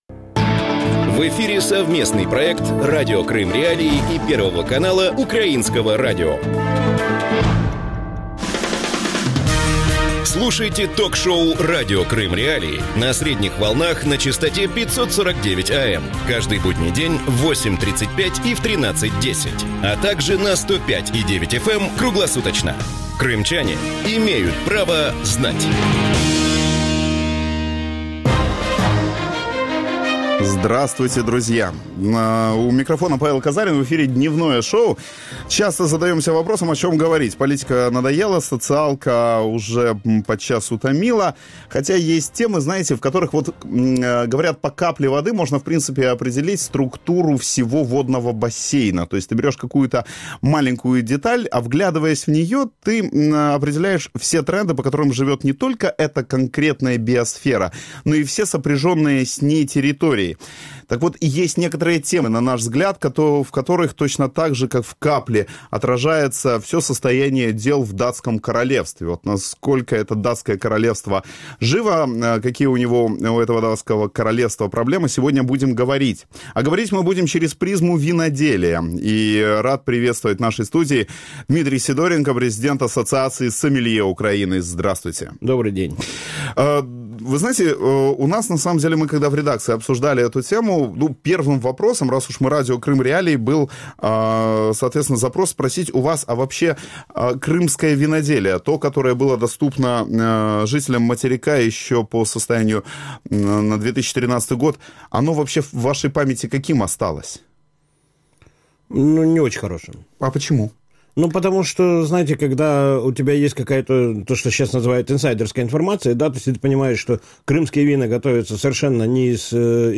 Гость студии